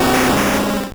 Cri de Dracaufeu dans Pokémon Or et Argent.